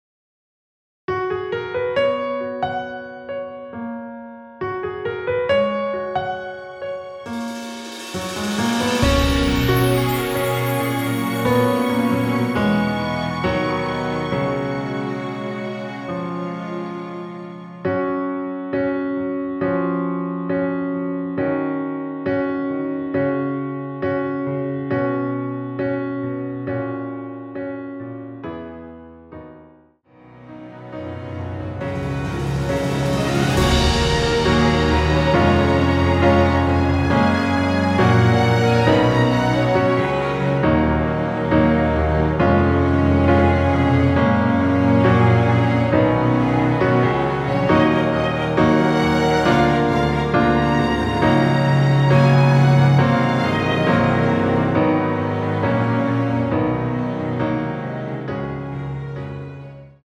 원키에서(-3)내린 MR입니다.
Gb
앞부분30초, 뒷부분30초씩 편집해서 올려 드리고 있습니다.
중간에 음이 끈어지고 다시 나오는 이유는